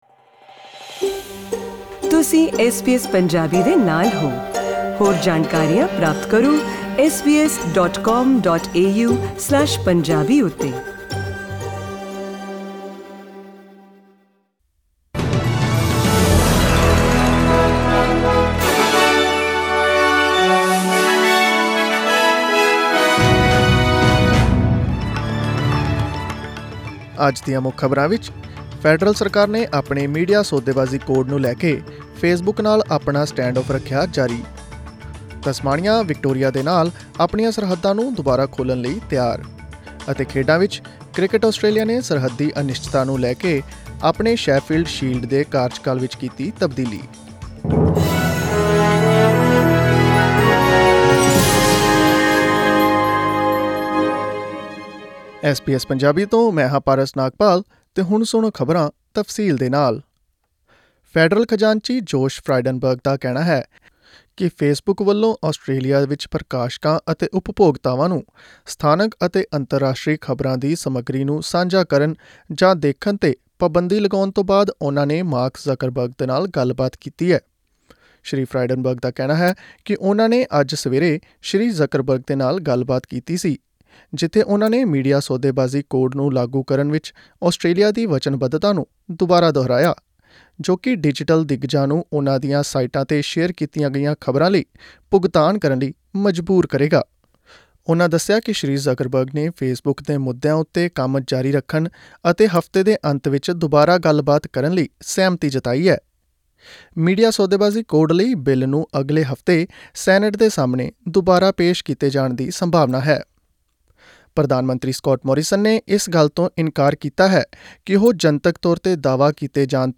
In this bulletin...The federal government continues its stand-off with Facebook over its media bargaining code. Tasmania to re-open its borders to Victoria.